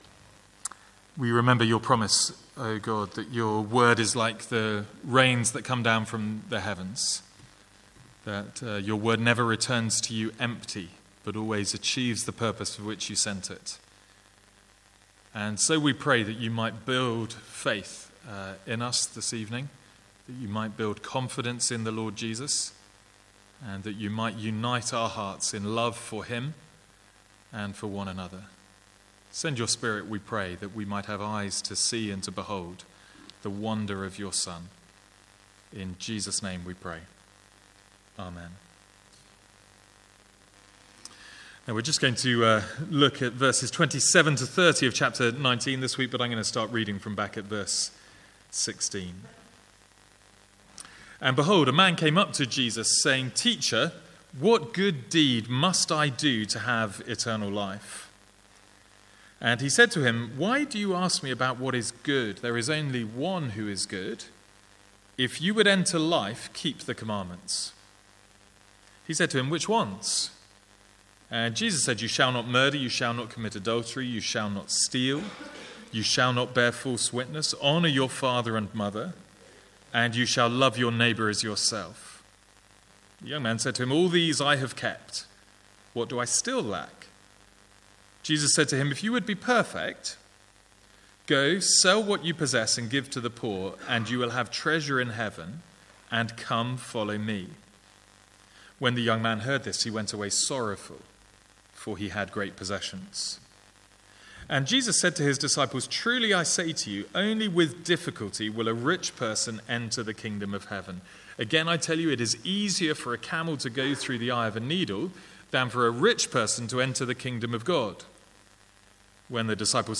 Sermons | St Andrews Free Church
From the Sunday evening series in Matthew.